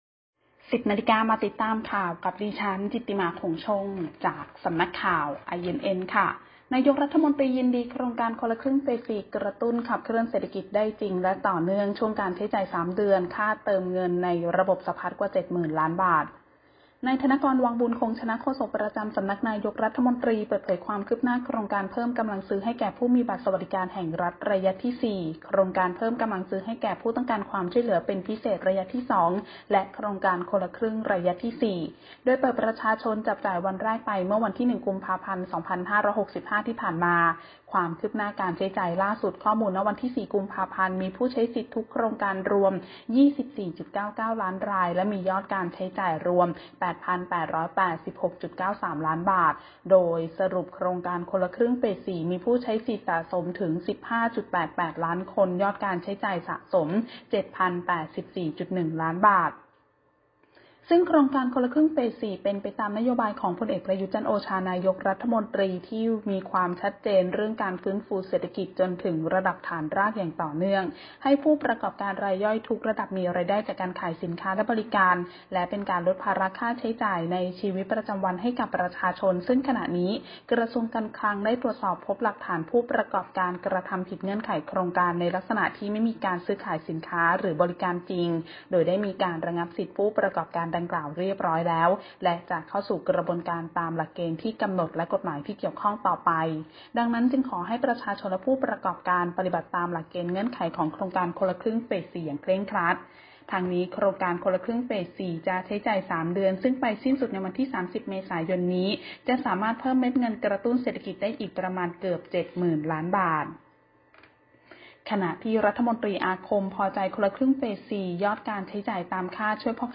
Video คลิปข่าวต้นชั่วโมง ข่าว